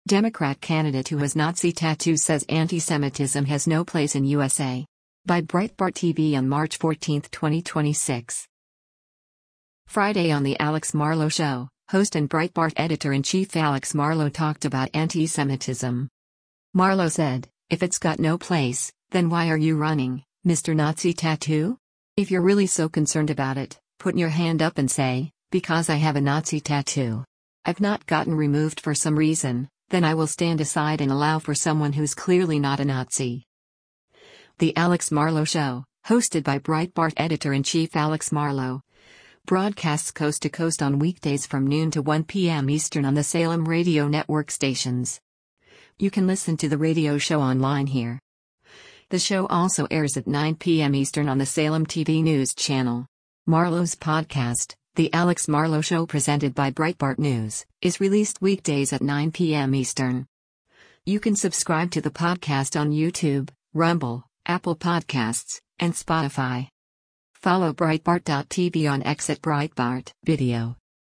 Friday on “The Alex Marlow Show,” host and Breitbart Editor-in-Chief Alex Marlow talked about antisemitism.